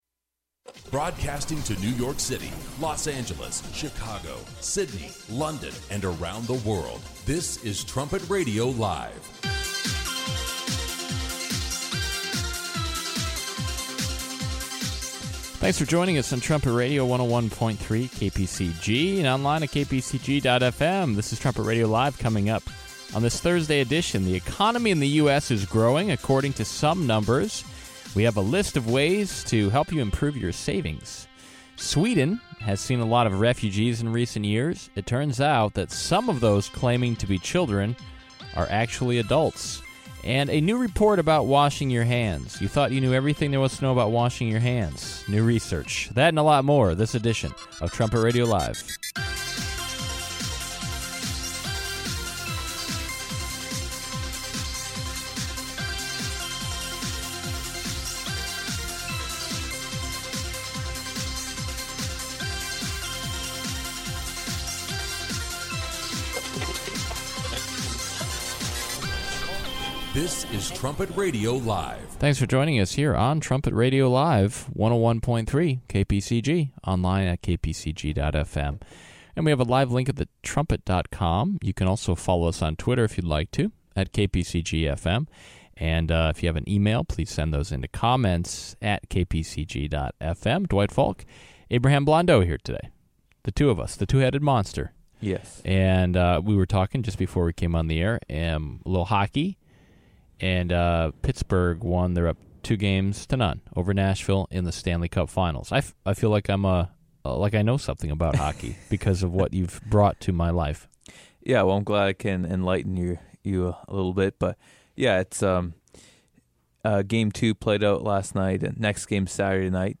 Trumpet Radio Live seeks to provide God’s view of the current headlines in an informative, stimulating, conversational and occasionally humorous way.
trumpet-radio-live-248-trl-today-u-s-economy-savings-tips.mp3